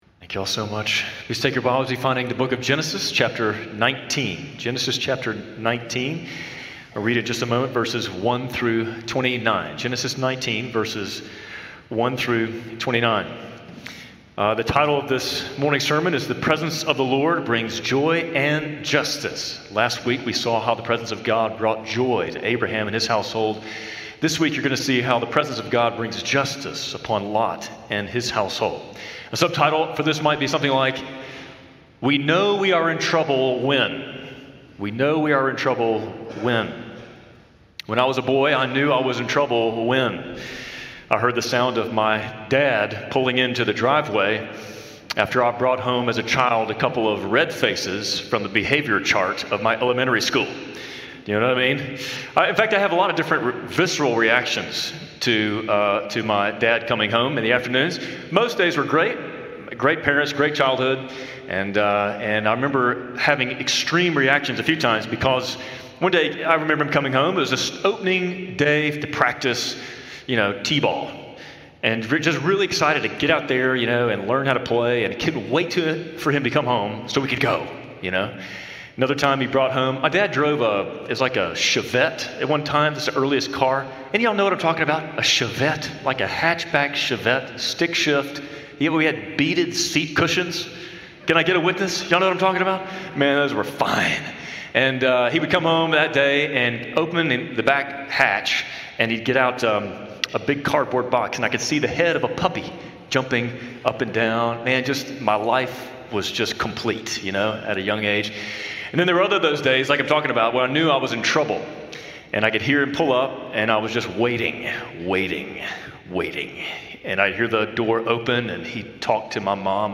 An episode by Spring Hill Baptist Sunday Sermons (Audio)